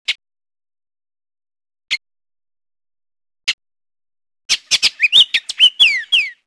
Baltimore-Trupial (Icterus galbula)
Library of Natural Sounds, Cornell Laboratory of Ornithology (71 KB)